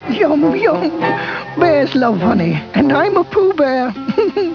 The voices of Pooh and his friends